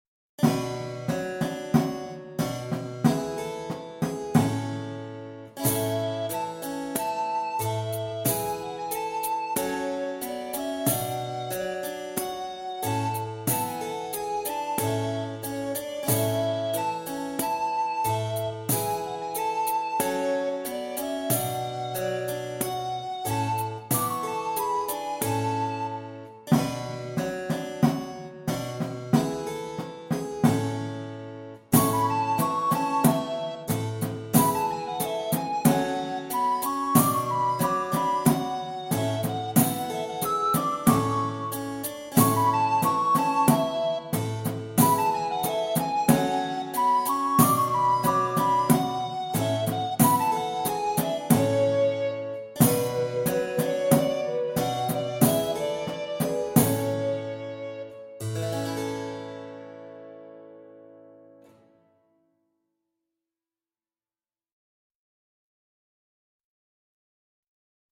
VS Maiden Fair (backing track)